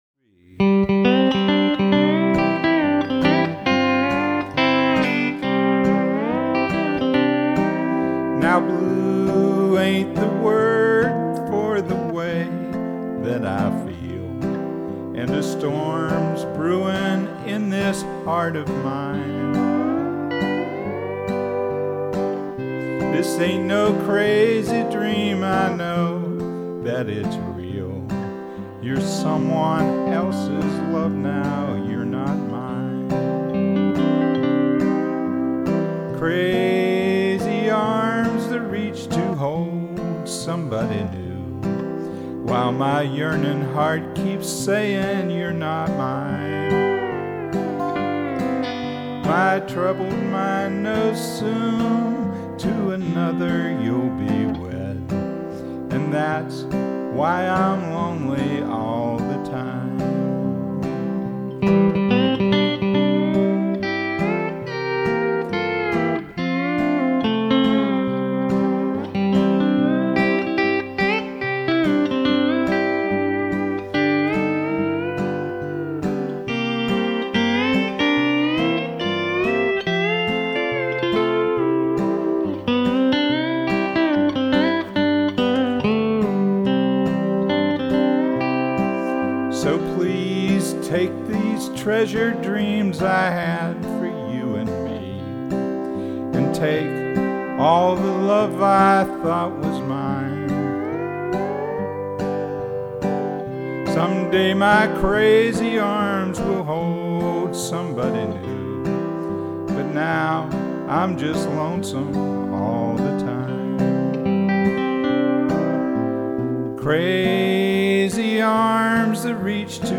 I wanted to get close to the E9th timbre on country songs.